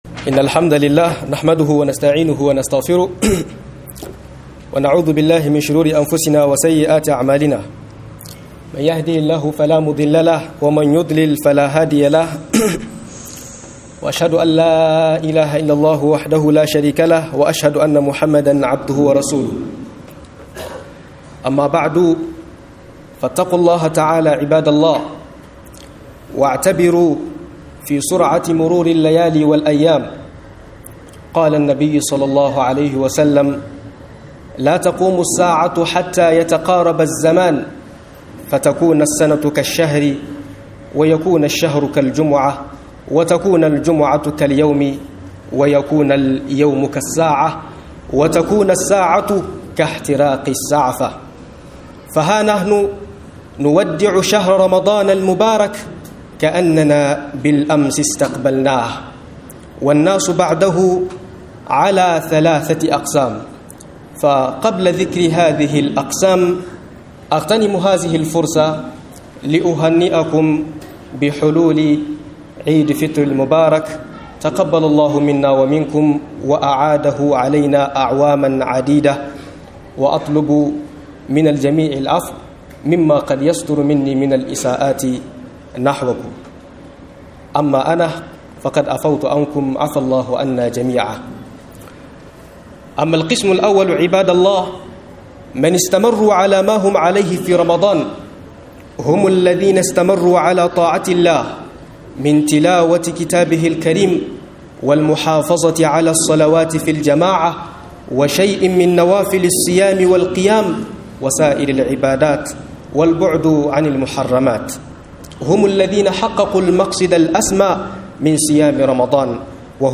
Mutane bayan Ramadan - MUHADARA